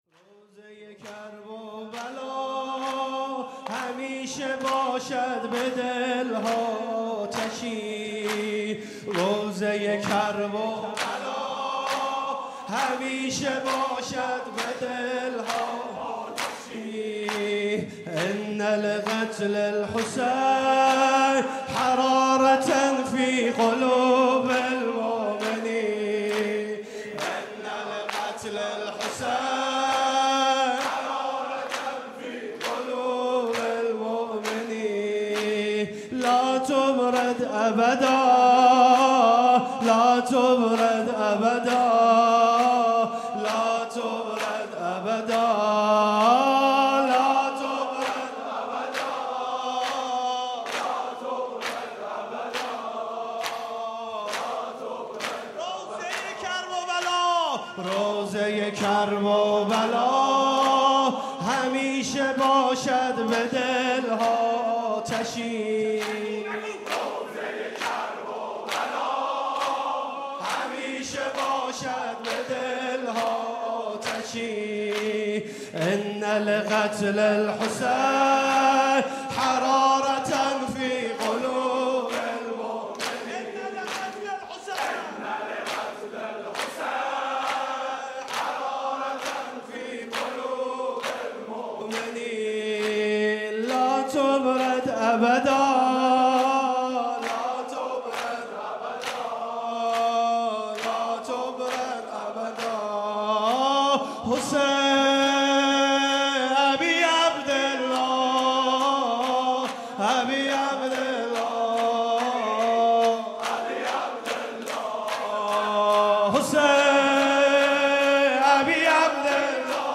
گلچين محرم 95 - واحد - روضه کرببلا